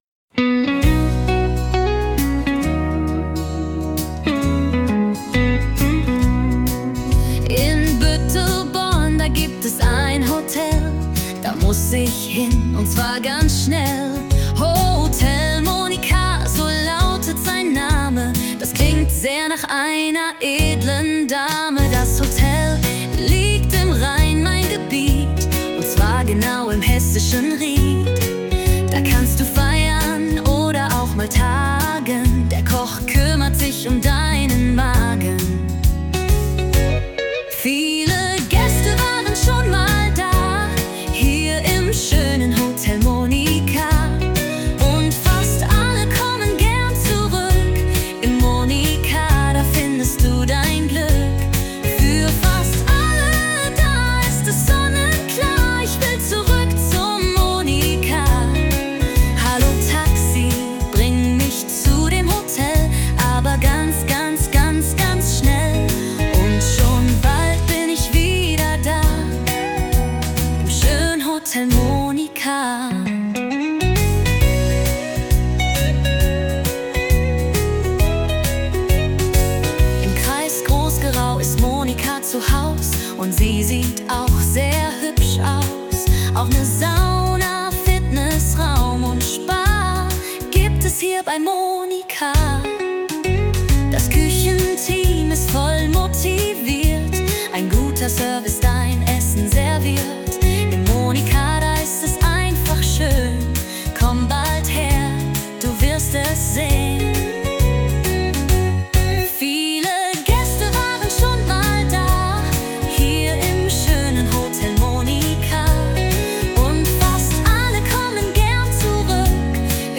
Mit Hilfe von KI erstellt.